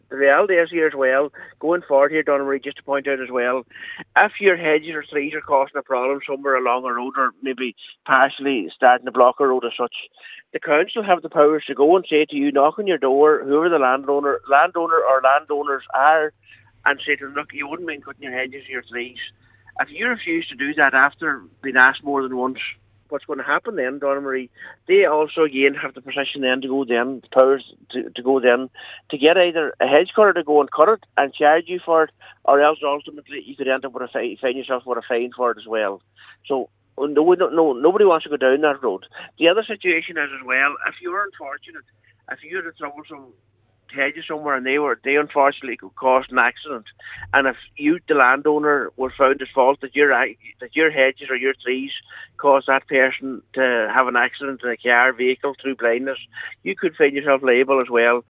Cllr McClafferty warns that those who refuse to act could face fines: